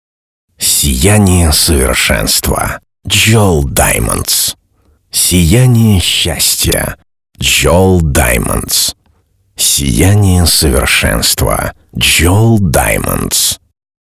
Нормальная ли это запись диктора?
Заказал на одном сайте сырую без обработки озвучку диктора.
У меня например в студии при записи, "ЭССС"-ки так сильно не выделяются.